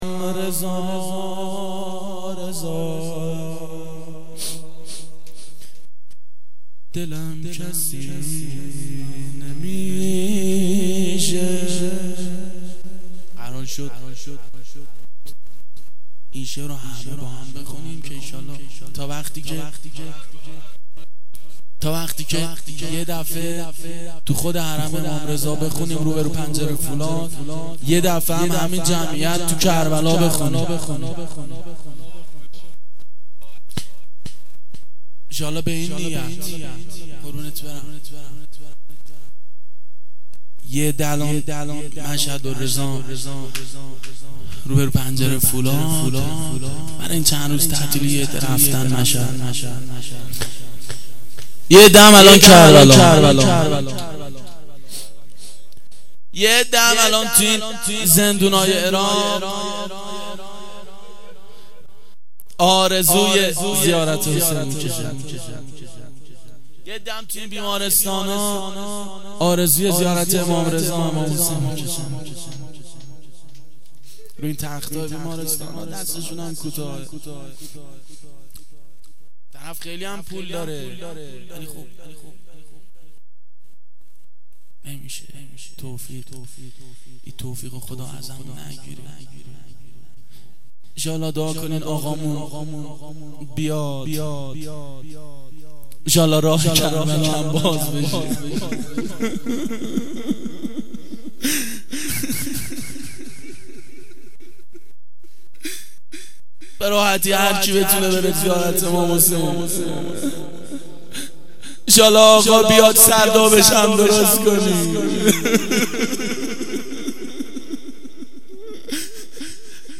گلچین جلسات هفتگی سال 1387